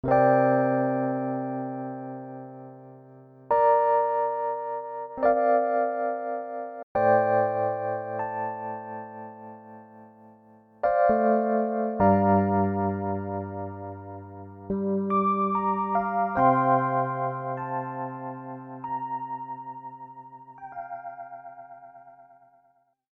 Vibrato and tremolo in a single unit give your samples Fender Rhodes 60's flair.
Each sequence is in a dry version and then effected in different ways.